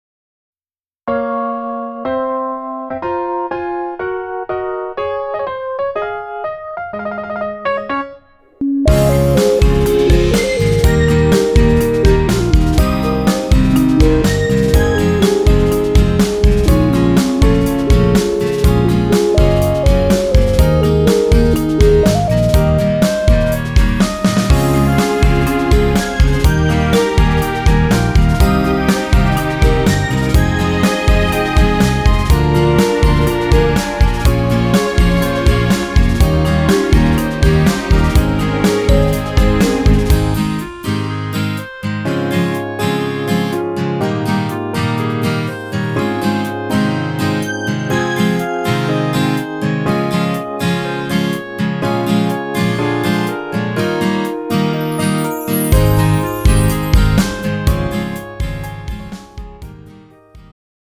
음정 남자키
장르 축가 구분 Pro MR